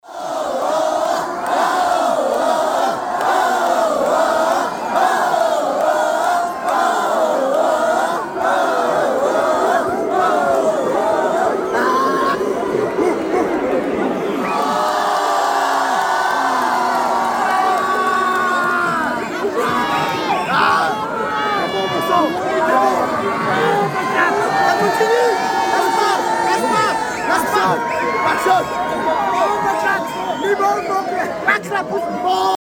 Звуки кричащей толпы скачать
Толпа кричит от испуга, восхищения, негодования.
tolpa-krichit.mp3